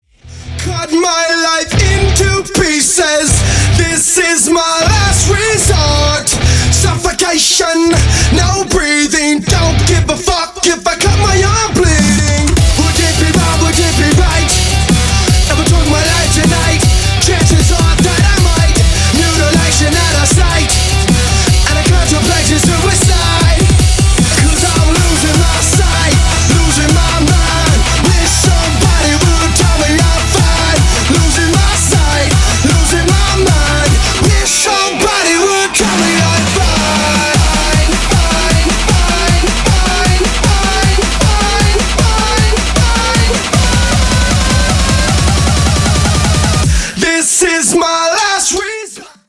• Качество: 320, Stereo
мужской вокал
громкие
жесткие
мощные
remix
Electronic
электрогитара
nu metal